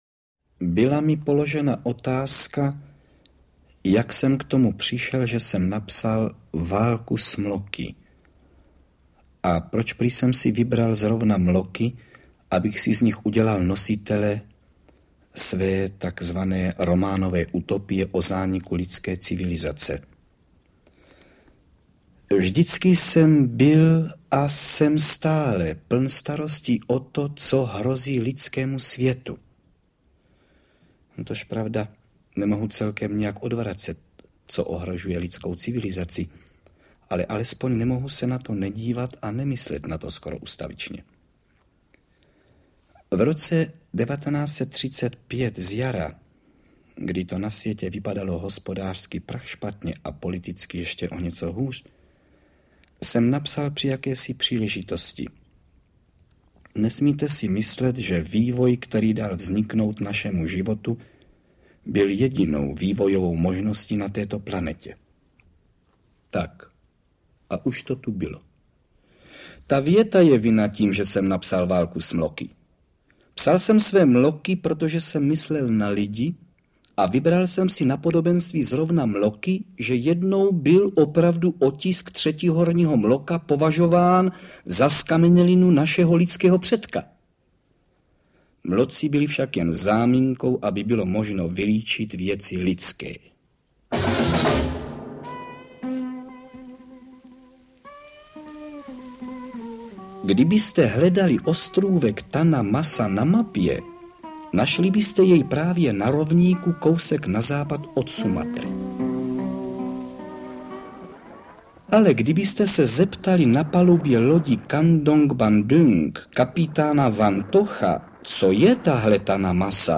Interpreti:  Jaroslava Adamová, Rudolf Deyl, František Filipovský, Karel Höger, Rudolf Hrušínský, Jan Pivec
Dramatizace známého protiválečnéo románu Karla. Autor v něm na příkladu bájných zvířat - obrovských mloků - upozorňuje na rozpínavost německého fašismu.